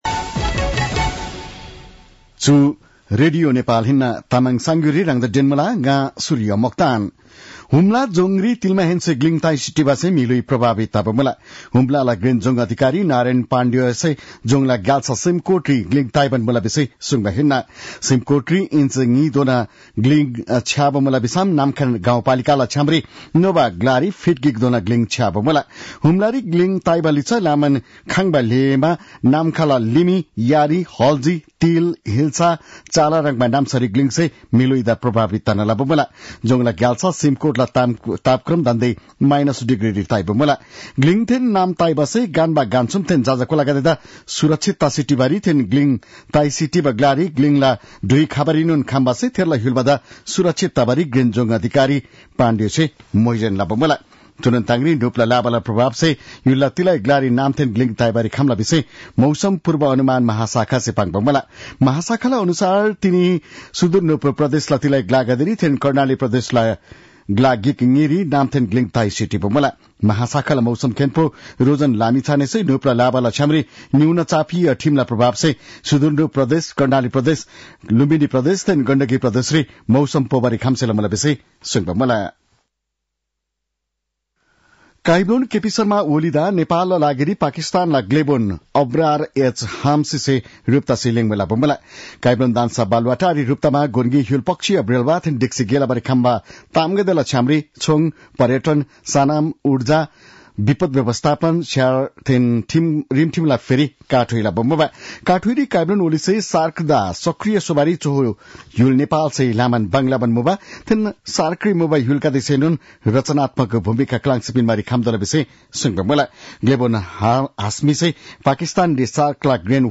तामाङ भाषाको समाचार : १० पुष , २०८१
Tamang-news-9-09.mp3